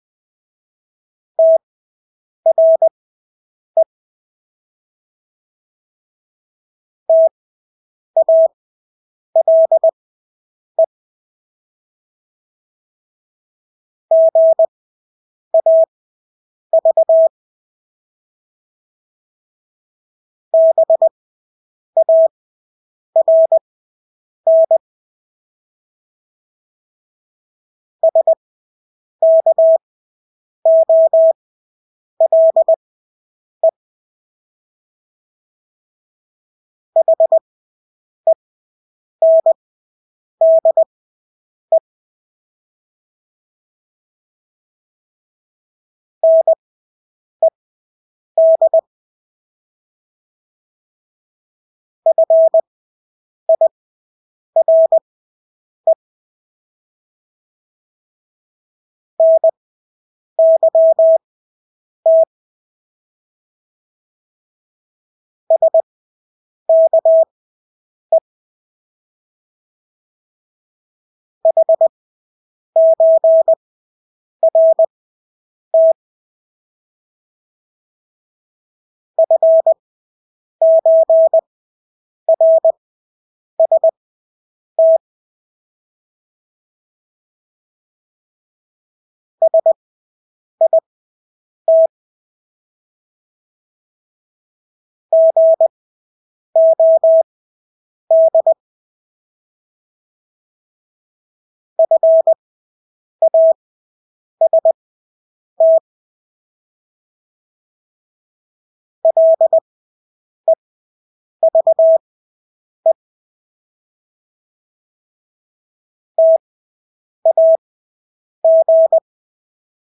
Danske ord 20wpm/8wpm | CW med Gnister